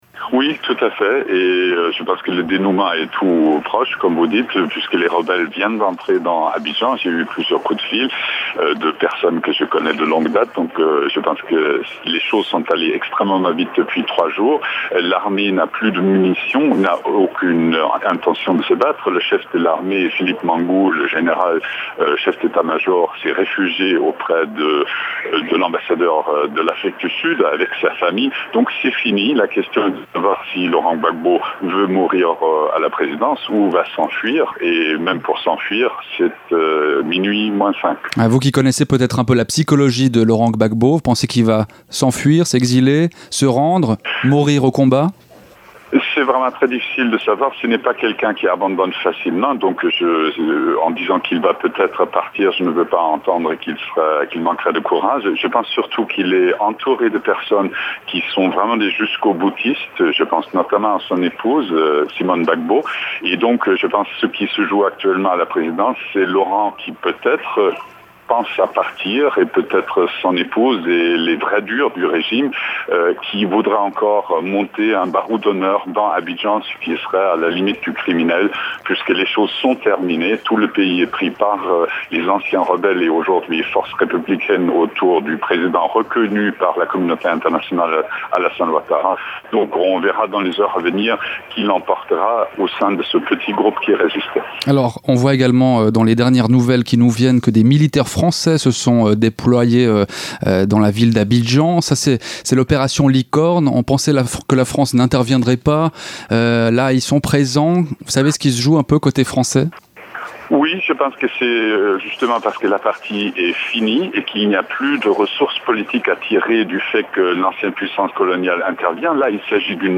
Stephen Smith, journaliste indépendant spécialiste de l’Afrique